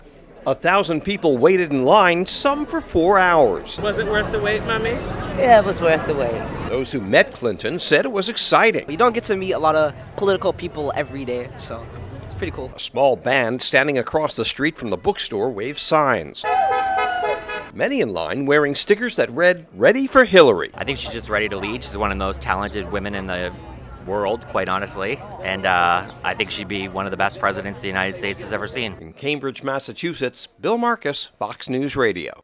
(CAMBRIDGE, MA) JUNE 16 – FORMER SECRETARY OF STATE HILLARY CLINTON MONDAY NIGHT AT HARVARD BOOK STORE IN CAMBRIDGE, MASSACHUSETTS FOR A SIGNING OF HER BOOK “HARD CHOICES”, A MEMOIR RECALLING HER TIME AS THE 67TH SECRETARY OF STATE. IT DREW MANY WHO SAID THEY WANTED TO SEE HER BECOME PRESIDENT.